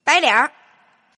Index of /client/common_mahjong_tianjin/mahjongjinghai/update/1115/res/sfx/tianjin/woman/